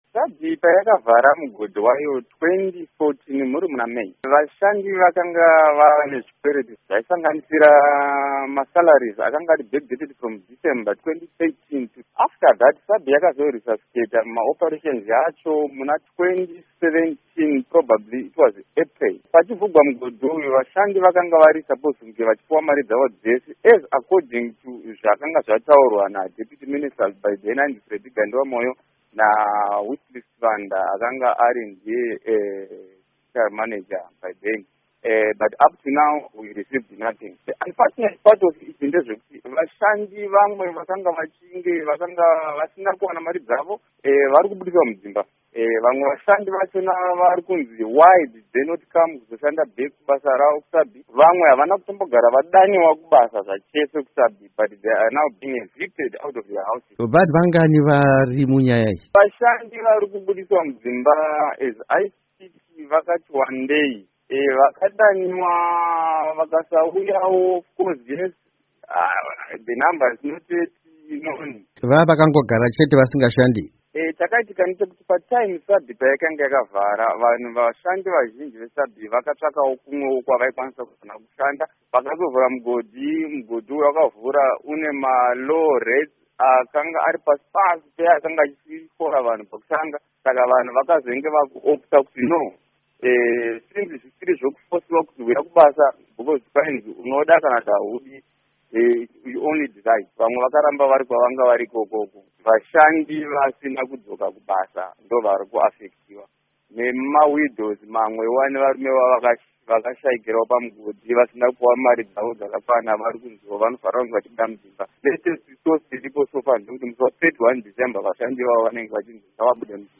Hurukuro neMushandi weSabi Mine